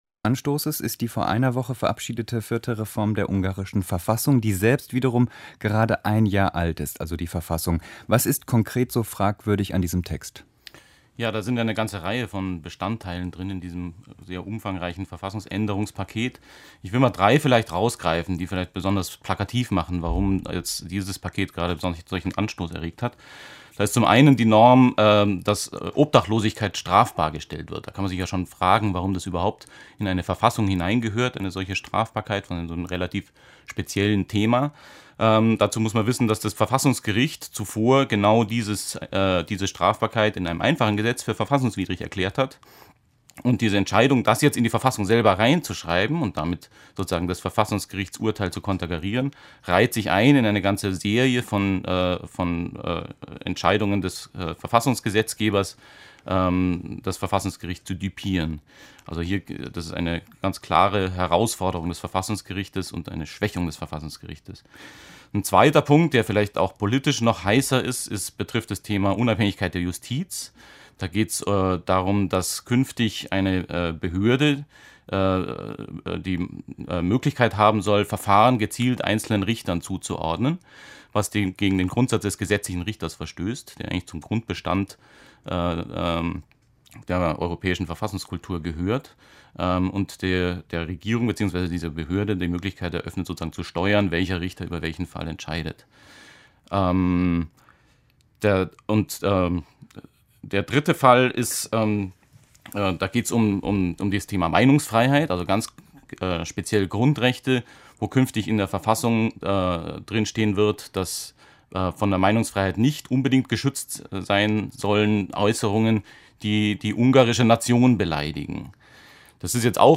Brüssel und die Kritik an der ungarischen Demokratie (Gespräch)